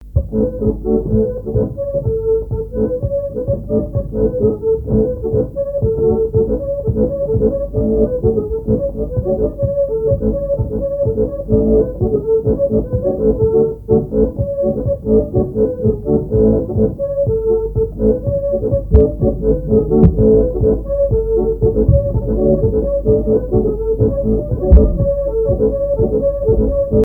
Chants brefs - A danser
danse : gigouillette
Répertoire à l'accordéon diatonique
Pièce musicale inédite